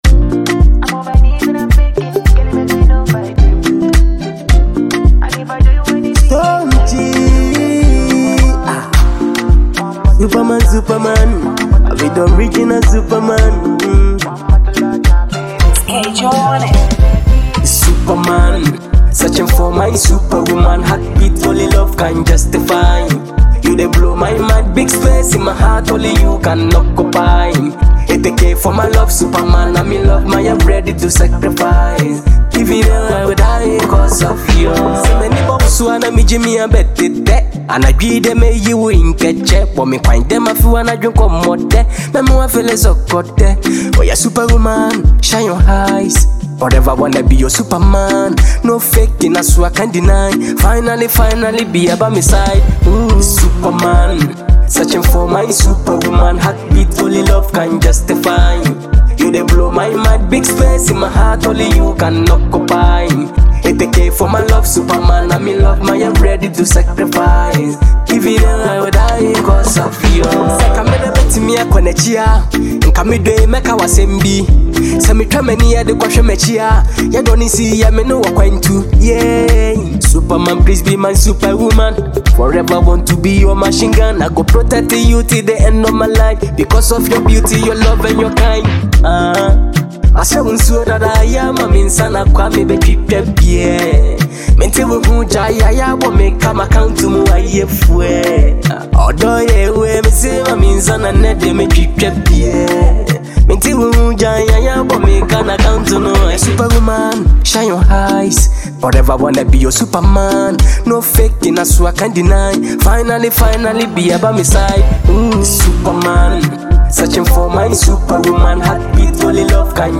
creating a rich and dynamic sound